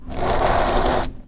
刮擦声 " 刮擦7
描述：在煤渣上拖动物体所发出的刮擦声
标签： 研磨 刷涂 刷涂
声道立体声